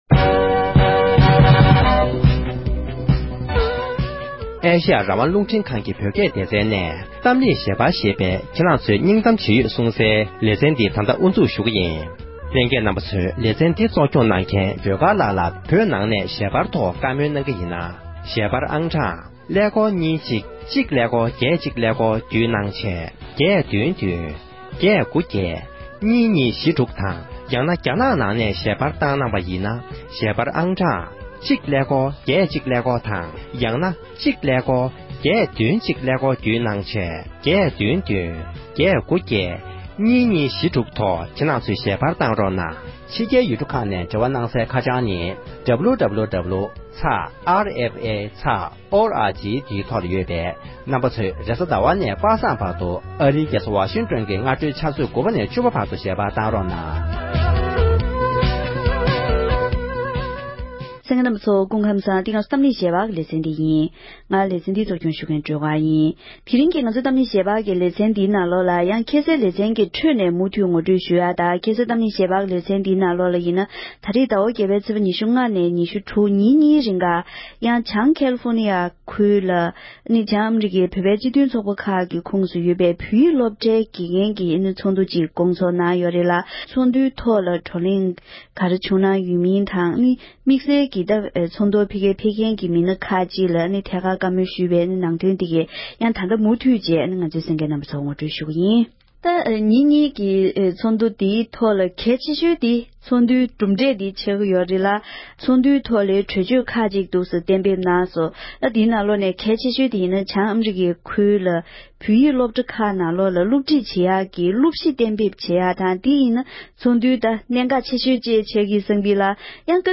༄༅༎དེ་རིང་གི་གཏམ་གླེང་ཞལ་པར་ཞེས་པའི་ལེ་ཚན་ནང་དུ། བྱང་ཀེ་ལི་ཕོར་ནི་ཡའི་ནང་སྐོང་ཚོགས་གནང་བའི་བྱང་ཨ་རིའི་བོད་པའི་སློབ་གྲྭ་ཁག་གི་བོད་ཡིག་སློབ་ཁྲིད་དང་འབྲེལ་བའི་ཚོགས་འདུ་དང་། དེ་བཞིན་དགེ་རྒན་ཁག་ཅིག་གིས་ས་གནས་ཀྱི་ཞིབ་ཕྲའི་གནས་ཚུལ་ངོ་སྤྲོད་གནང་བ་ཞིག་གསན་རོགས་ཞུ༎